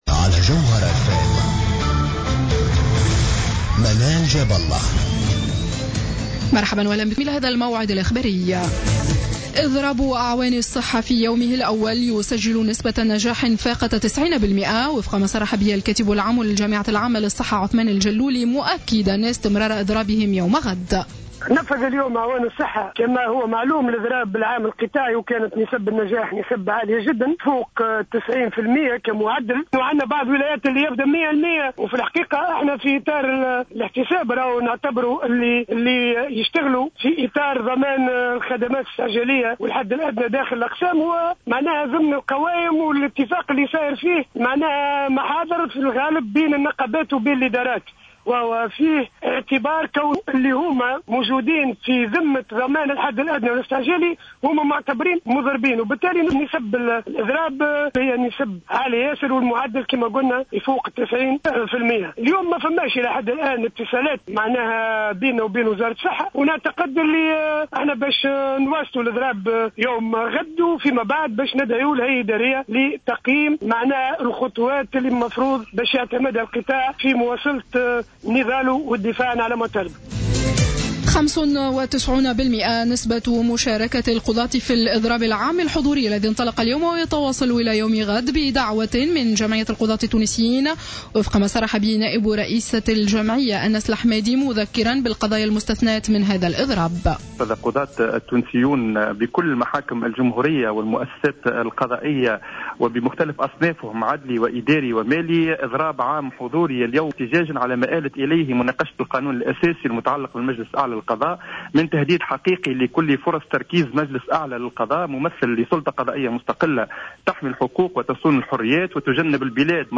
نشرة أخبار السابعة مساء ليوم الثلاثاء 28 أفريل 2015